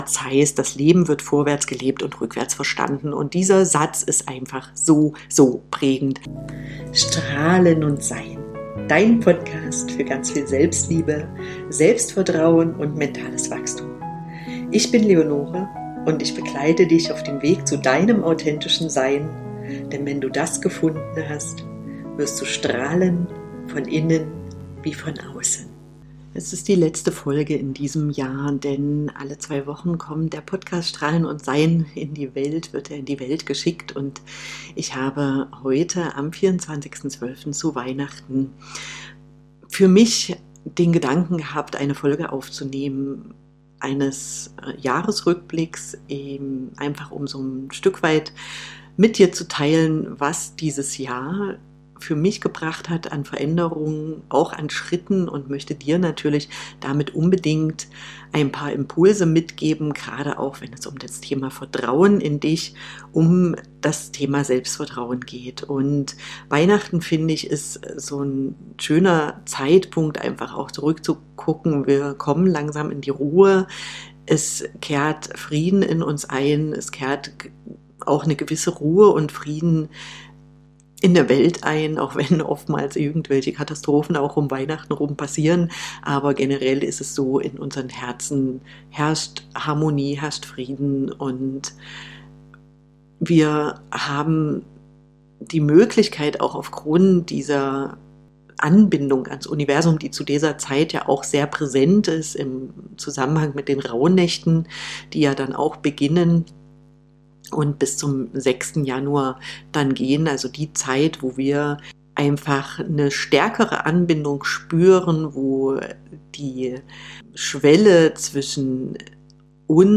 Diese Folge ist eine Einladung: innezuhalten zurückzublicken und zu spüren, was dieses Jahr dich gelehrt hat Am Ende der Folge erwartet dich eine sanfte innere Reise, eine kleine Meditation, um dein Jahr bewusst abzuschließen, zu würdigen, was war und daraus deine nächsten Schritte für das neue Jahr abzuleiten.